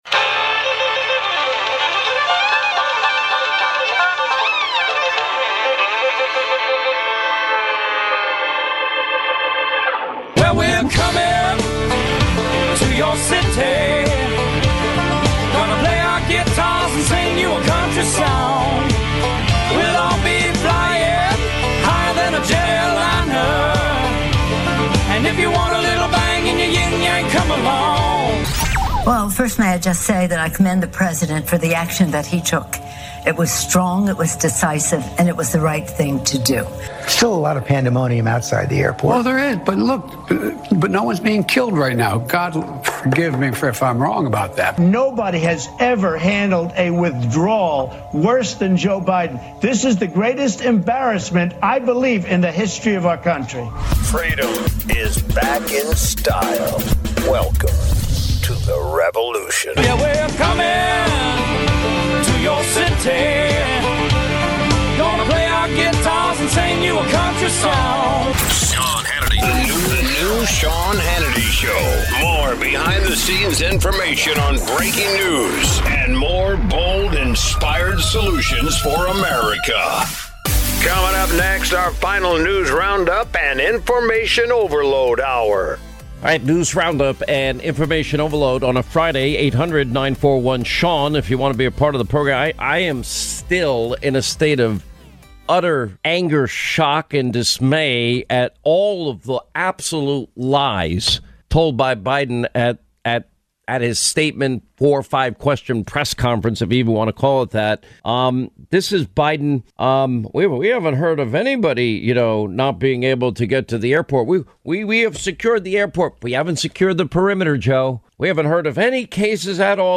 The Drill Down's own Peter Schweizer and former congressman Jason Chaffetz speak with Sean Hannity about the lies coming out of the White House. They cover the chaos in Kabul, Biden's mental state, and who knew what and when in the wake of the worst foreign policy disaster in decades.